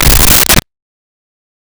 Creature Footstep 01
Creature Footstep 01.wav